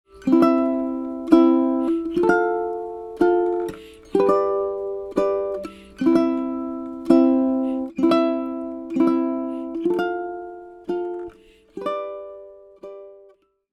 Begin by memorizing the names and shapes of the new chords: Amin, F, G. The suffix "min" stands for minor.
Down 2X Strum | Strum downwards on beats 1 and 3.
First_and_Only_uke_D2xstrum.mp3